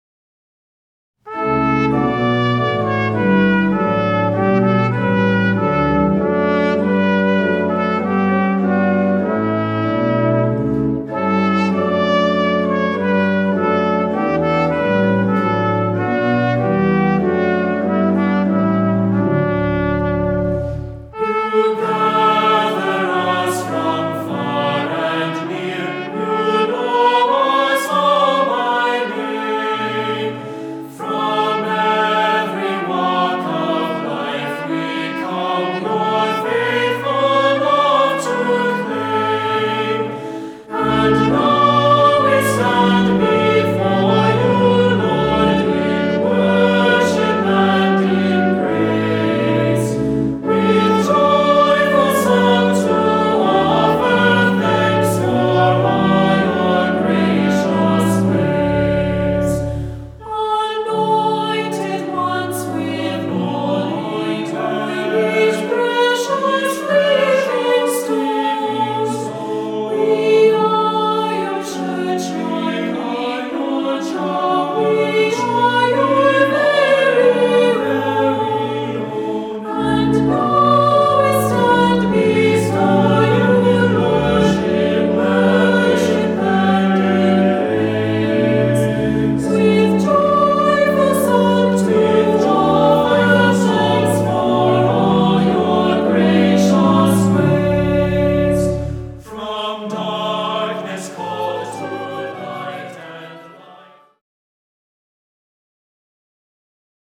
Voicing: SAB; Descant; Assembly